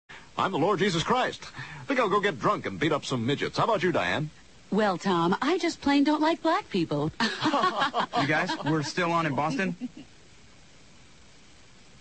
• Voice Tones Ringtones